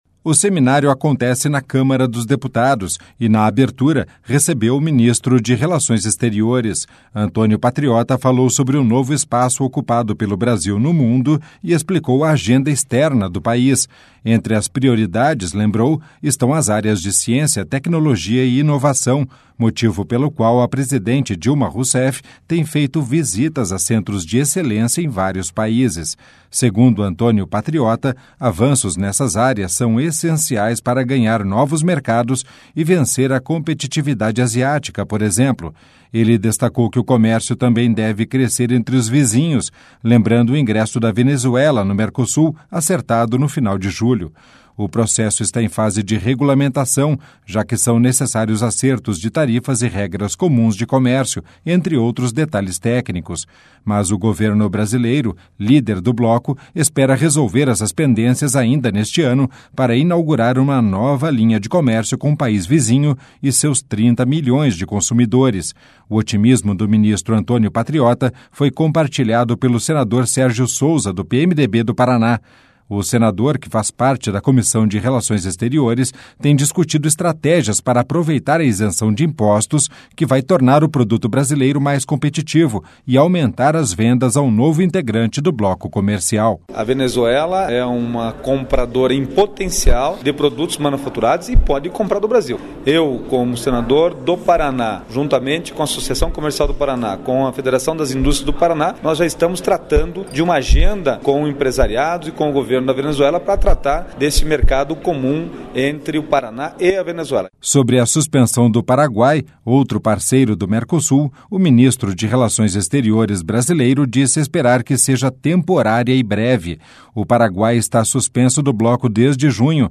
(Repórter) O seminário acontece na Câmara dos Deputados e